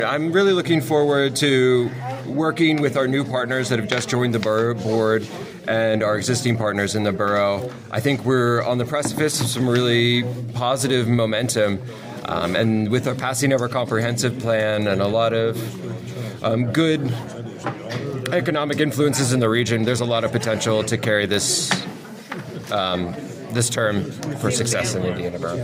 The board then appointed Jonathan Smith by a 7-1 vote.  Smith talks about what he looks forward to in his first term as council president.